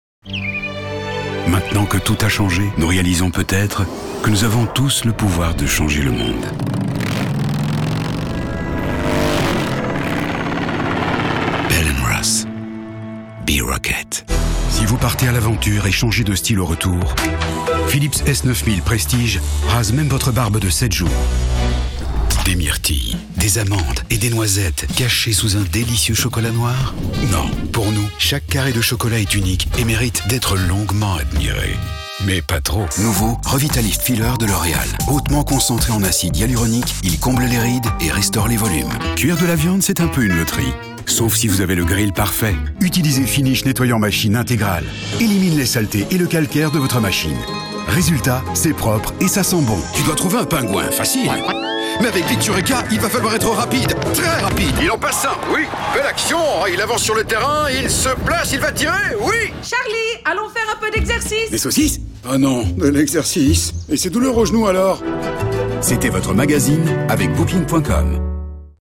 With 30 years of experience forged by numerous projects around the world, I can help you enhance your message or your brand with a warm, reassuring and, above all, a male voice that speaks to you.
Sprechprobe: Sonstiges (Muttersprache):